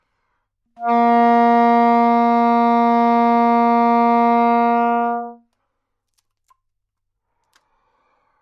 萨克斯高音单音 " 萨克斯高音 A3
描述：在巴塞罗那Universitat Pompeu Fabra音乐技术集团的goodsounds.org项目的背景下录制。单音乐器声音的Goodsound数据集。
标签： 好声音 萨克斯 单注 多样本 Asharp3 女高音 纽曼-U87
声道立体声